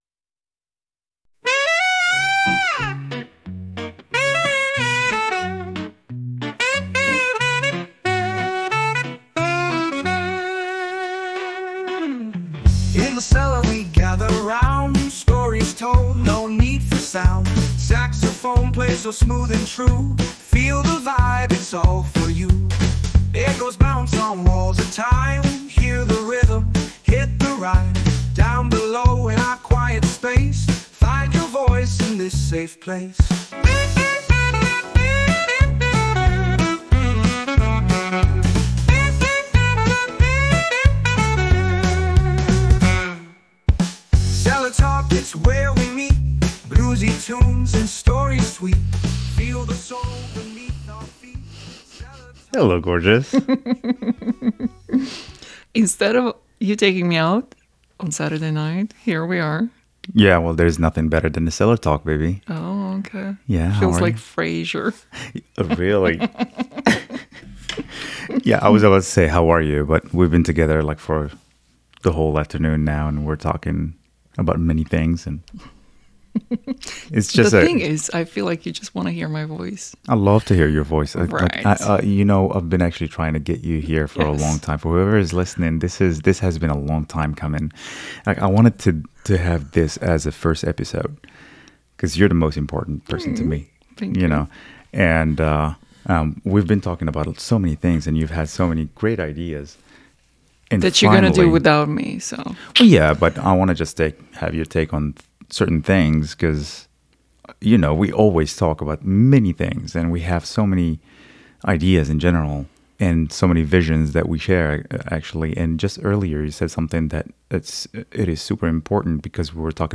This episode is a heartfelt conversation that offers a glimpse into our world together.